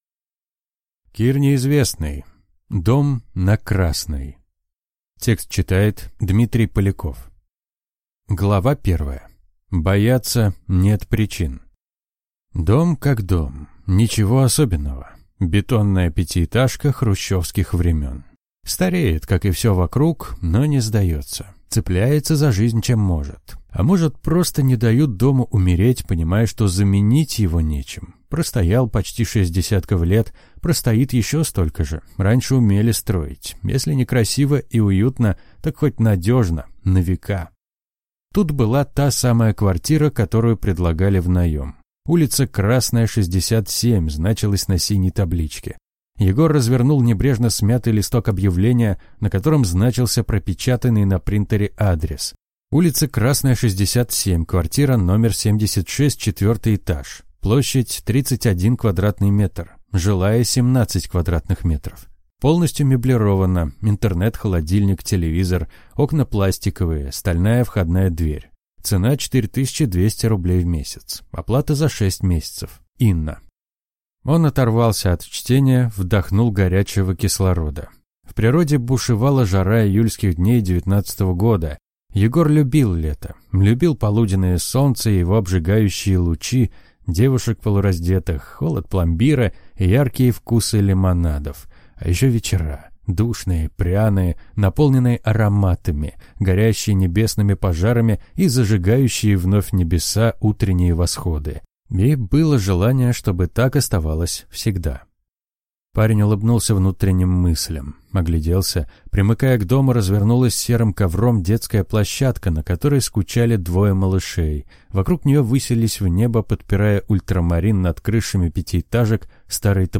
Аудиокнига Дом на Красной | Библиотека аудиокниг